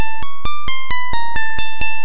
A loose riff
Channels: 2 (stereo)